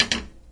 烹饪 " 将煎锅
标签： 厨房 桌子 推杆
声道立体声